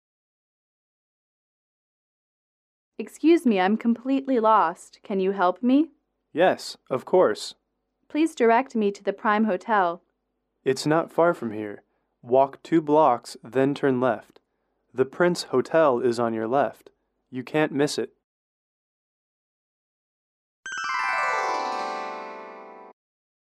英语主题情景短对话12-1：迷路(MP3)